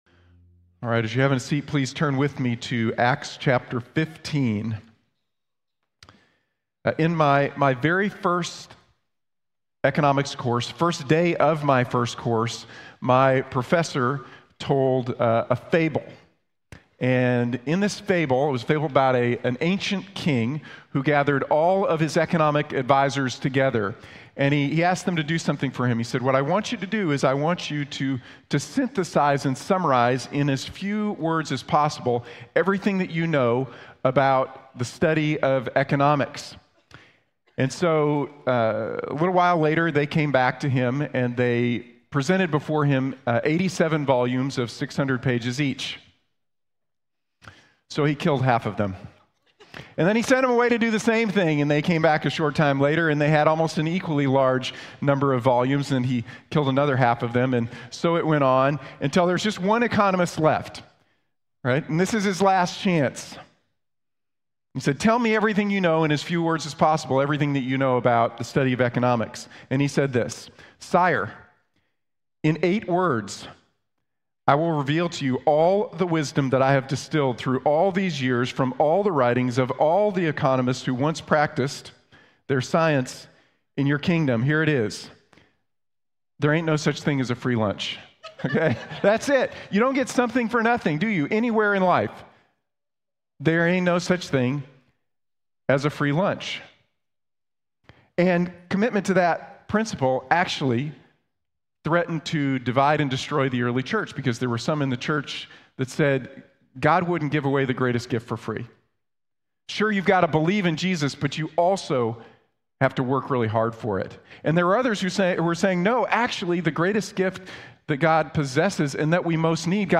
La Gracia de Dios es Nuestra Única Esperanza | Sermón | Iglesia Bíblica de la Gracia